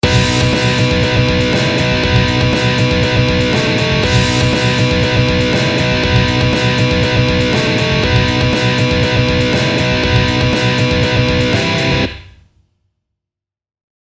🎸eadgbe 7x98xx -> 0098xx -> 7x98xx -> 7x78xx -> 🔁 :3